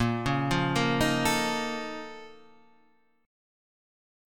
A#mM11 Chord